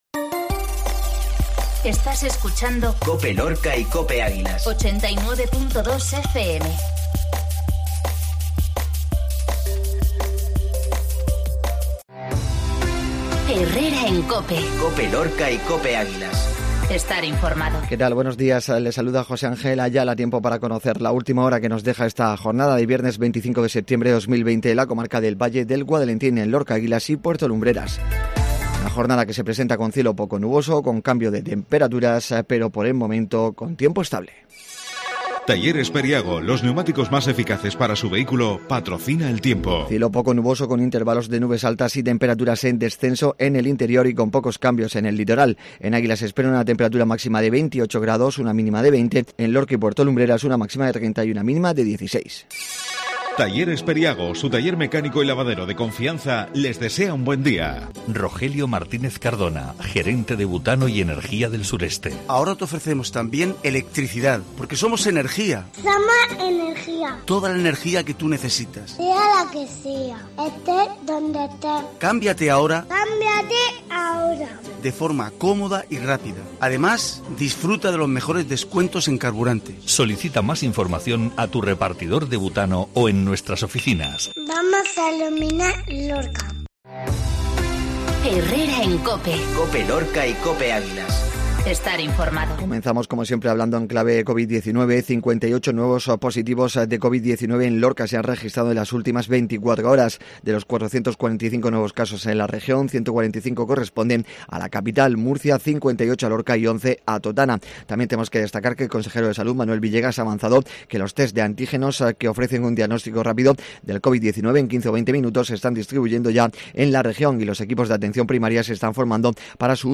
INFORMATIVO MATINAL VIERNES 25